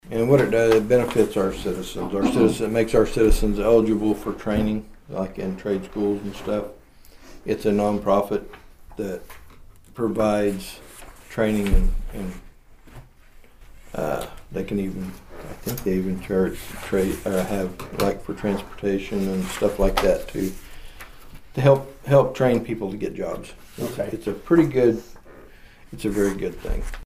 Commissioner Troy Friddle talked about what the agreement entails.